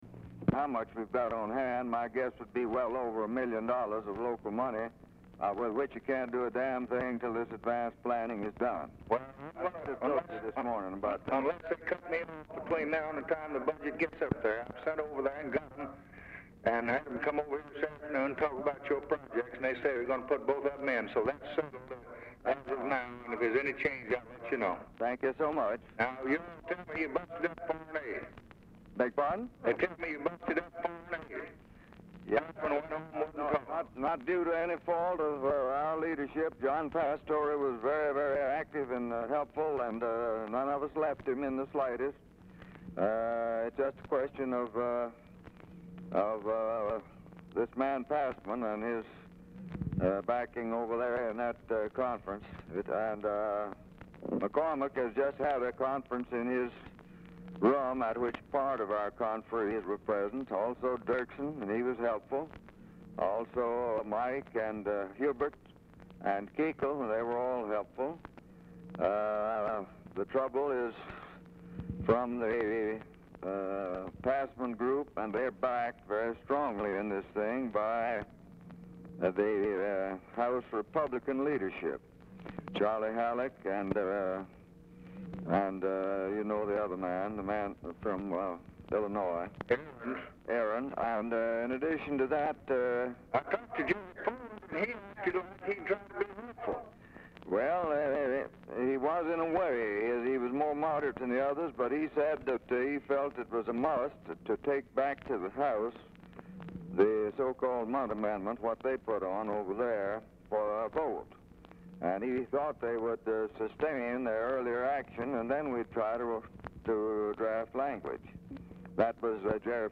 Telephone conversation # 594, sound recording, LBJ and SPESSARD HOLLAND, 12/20/1963, 5:47PM
POOR SOUND QUALITY
Format Dictation belt
Location Of Speaker 1 Oval Office or unknown location